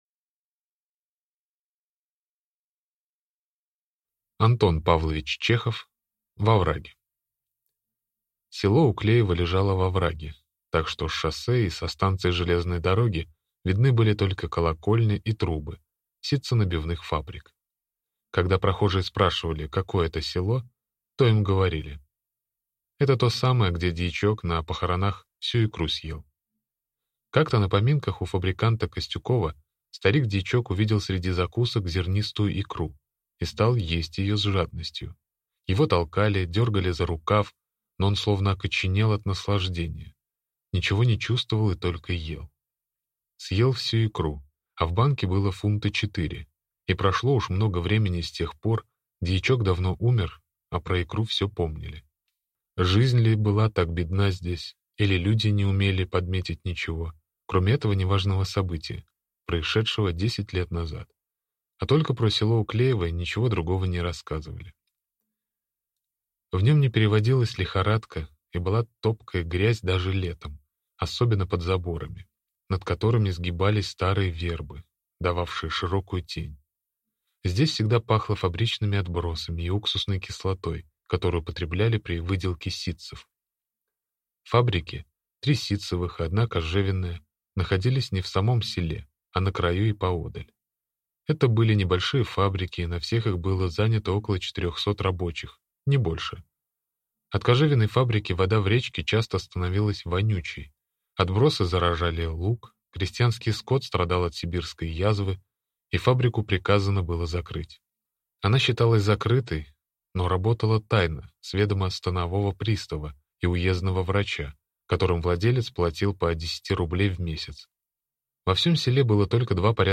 Аудиокнига В овраге | Библиотека аудиокниг